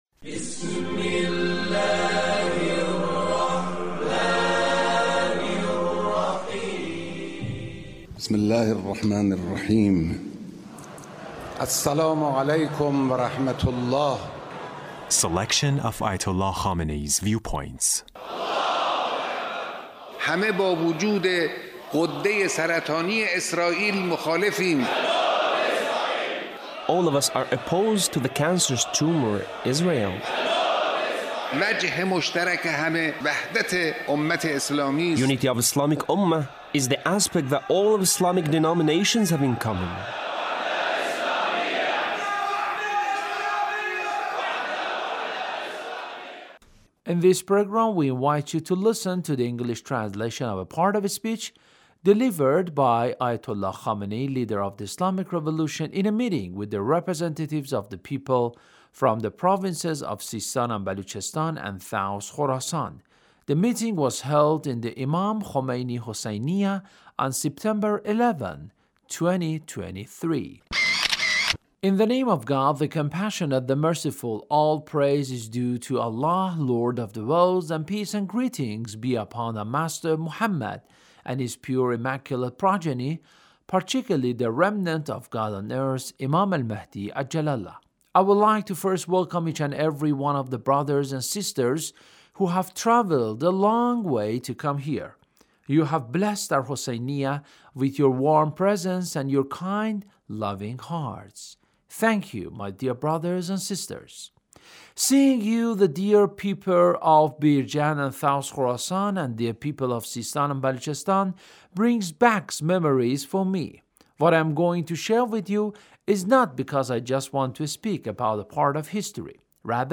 Leader's Speech with the people of SISTAAN